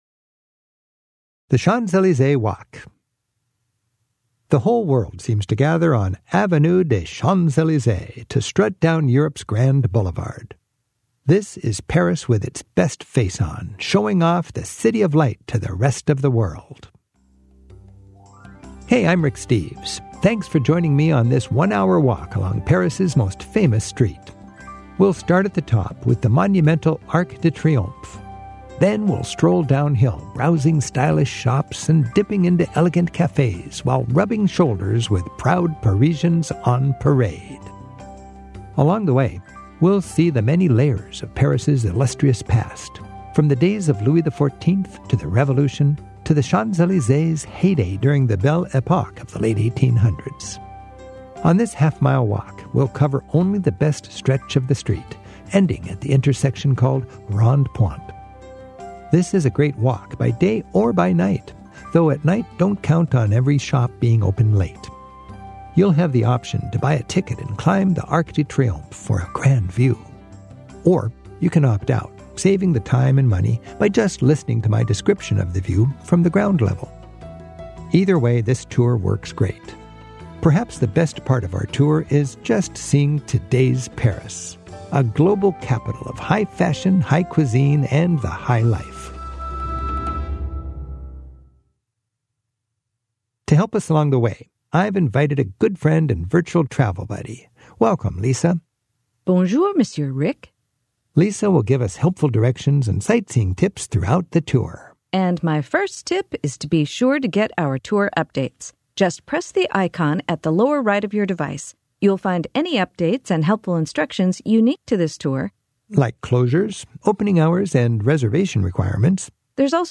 Paris Audio Tours